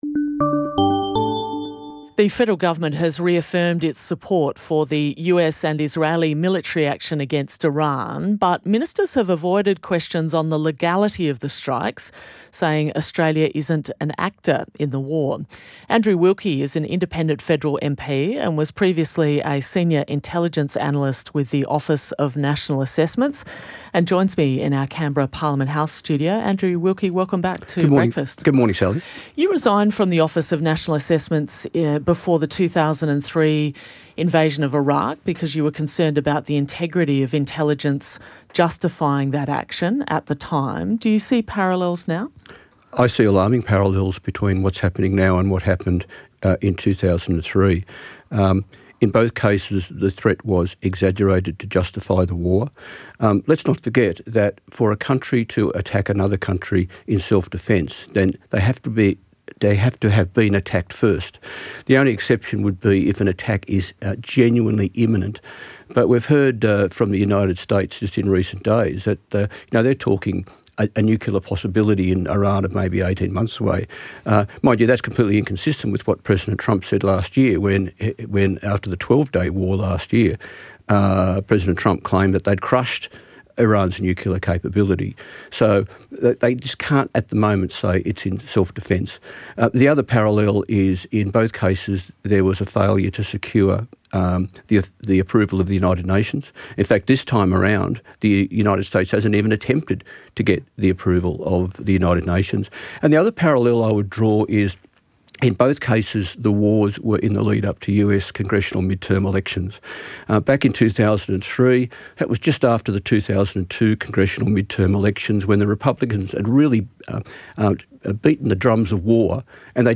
• GUEST: Andrew Wilkie, independent federal MP for Clark, and previously served as a senior intelligence analyst with the Office of National Assessments